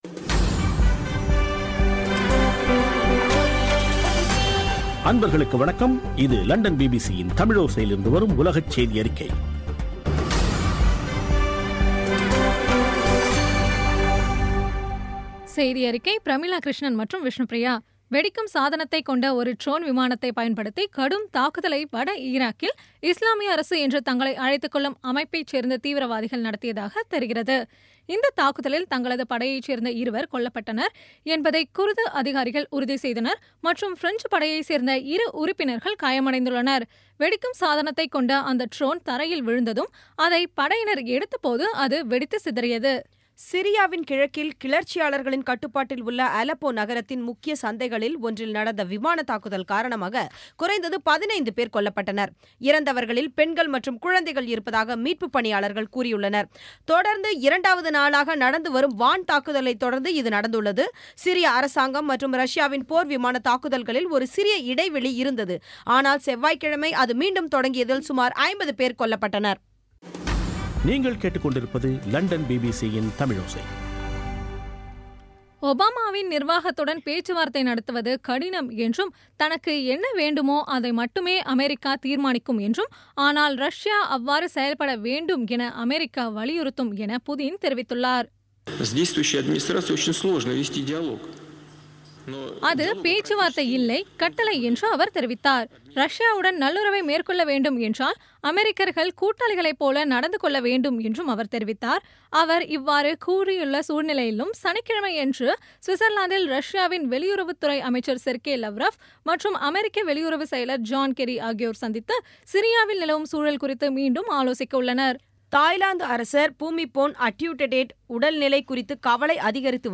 பி பி சி தமிழோசை செய்தியறிக்கை (12/09/2016)